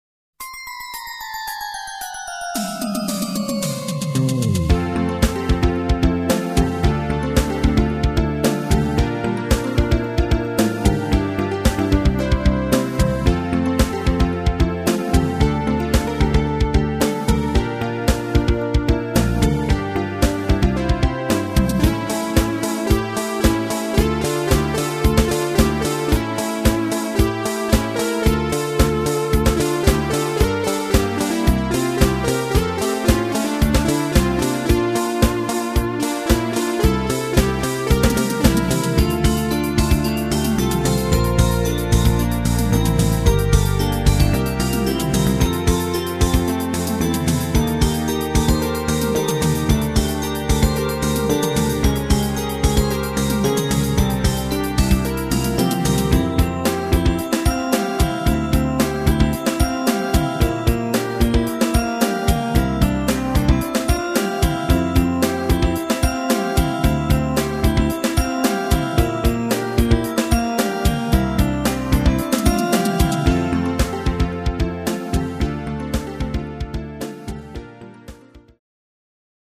ＨＰで公開してるものの初期バージョンです。
※音源はＳＣ８８Ｐｒｏ